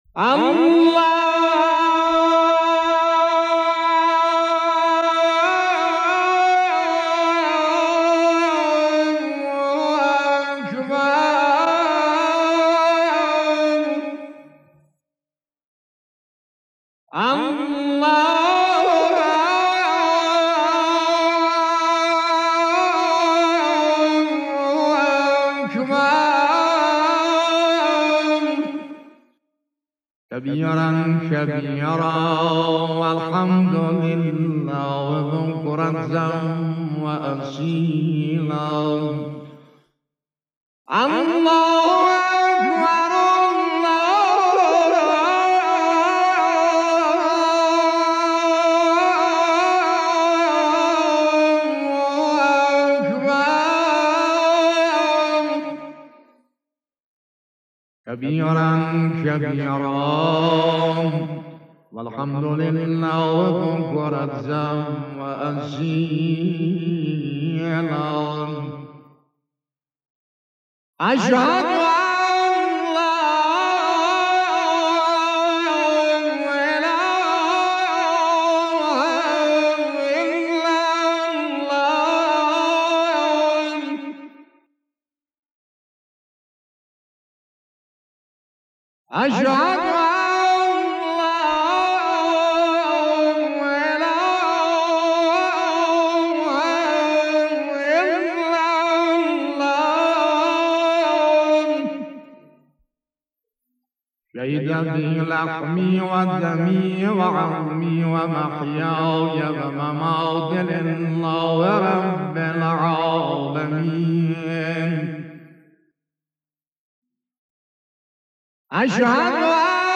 صوت با کیفیت اذان مرحوم سلیم موذن زاده اردبیلی
دانلود فایل صوتی با کیفیت از گلبانگ اذان مرحوم سلیم موذن زاده اردبیلی.